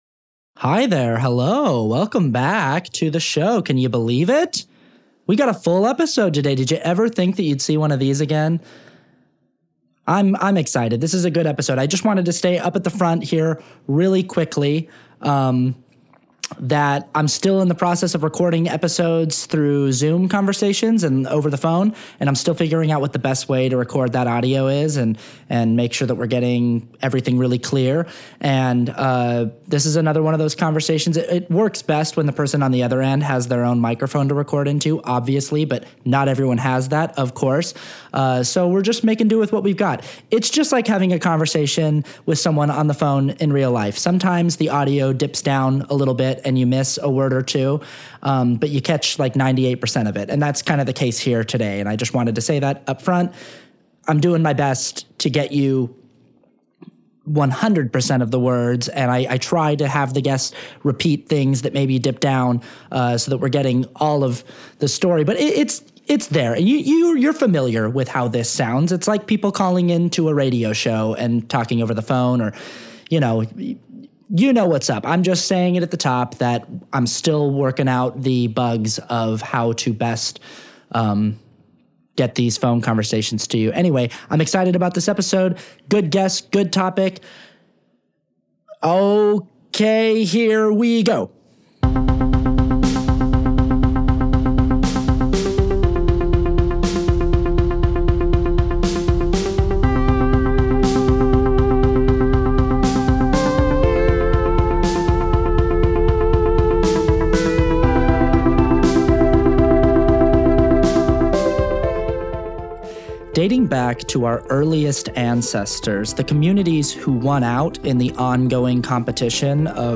in the blanket fort this week via Zoom